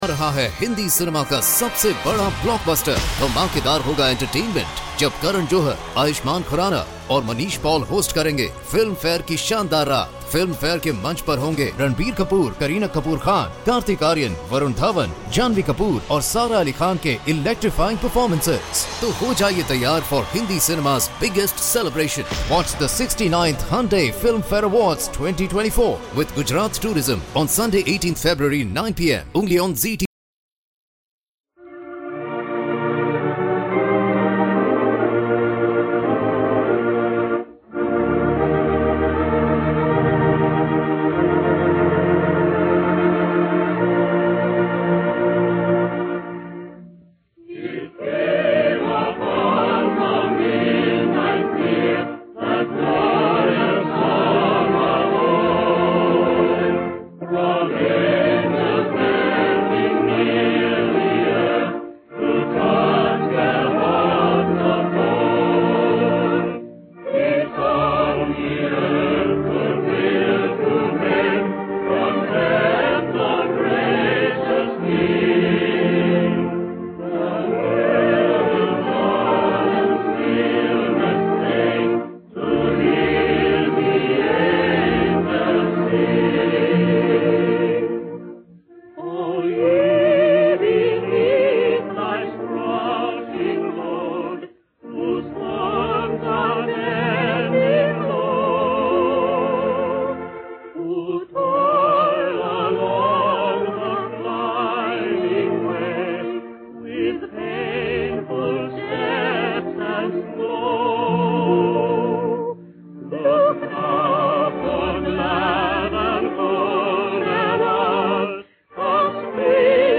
OTR Christmas Shows - Chorus and Organ - It Came Upon A Midnight Clear - 1943-11-xx 048 V-Disc B